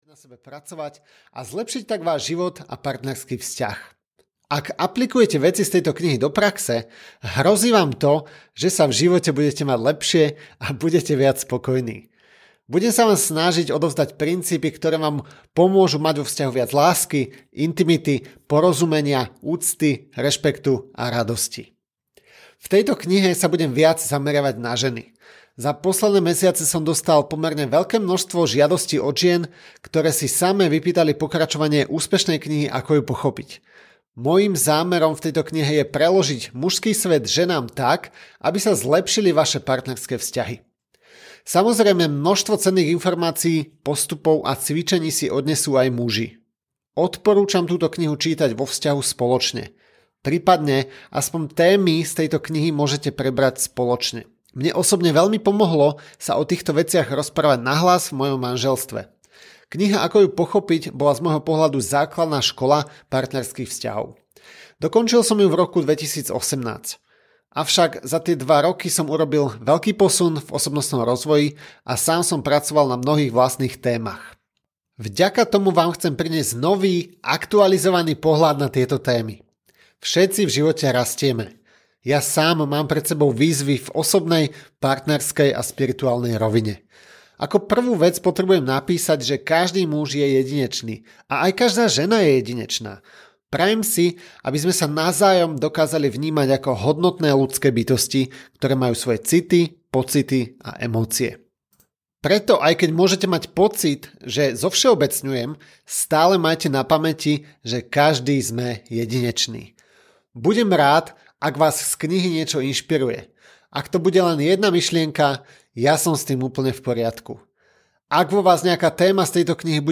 Ukázka z knihy
pravda-o-muzoch-audiokniha